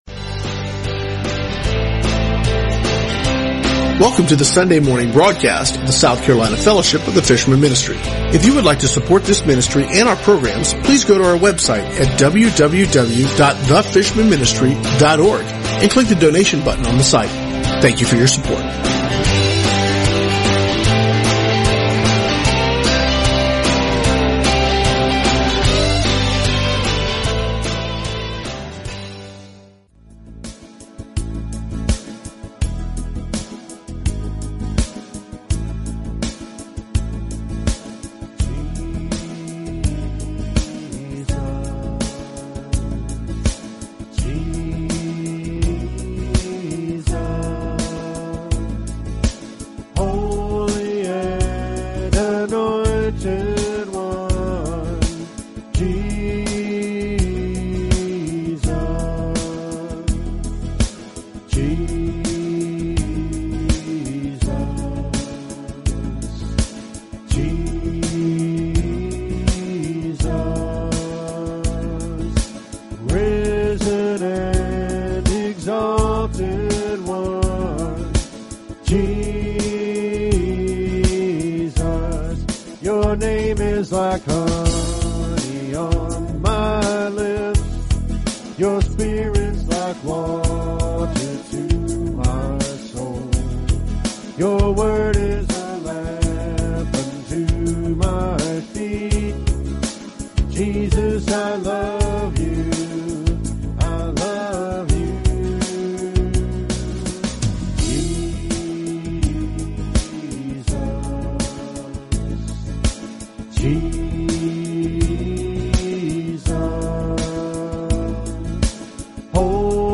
Sunday South Carolina Church Service 01/17/2016 | The Fishermen Ministry